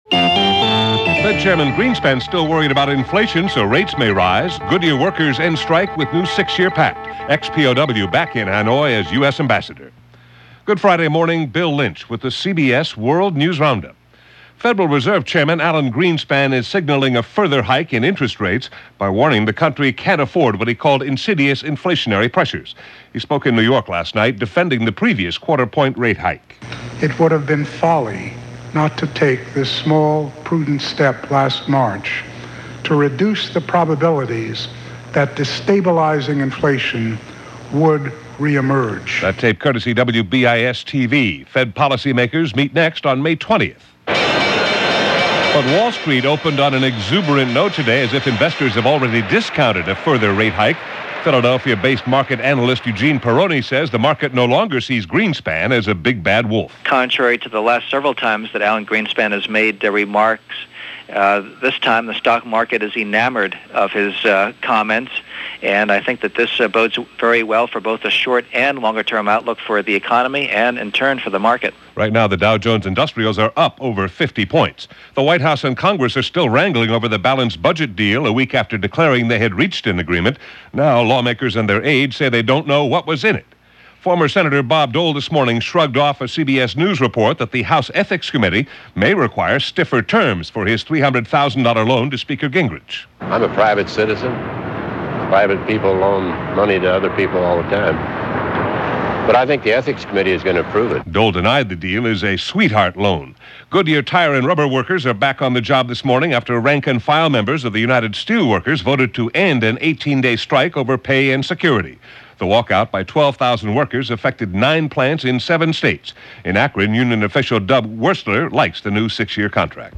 All that, and so much more for this 9th Day of May in 1997 as reported by The CBS World News Roundup.